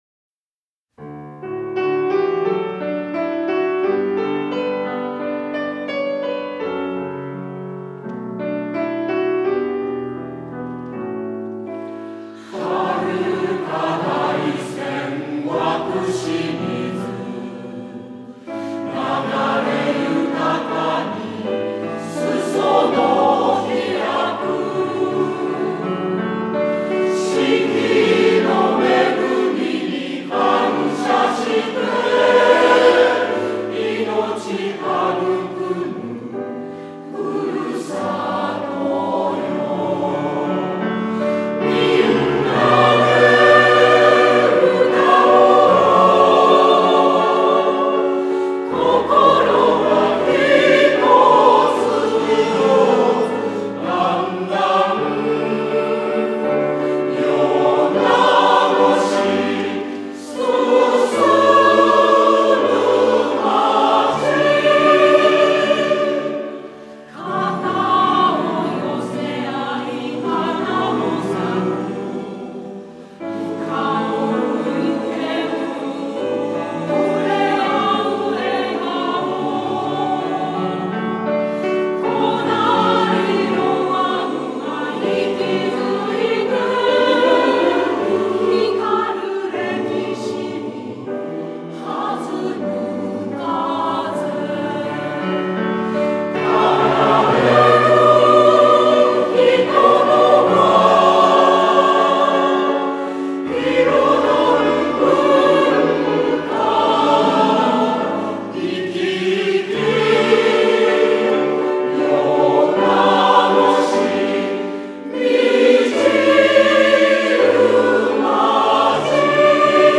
合唱バージョン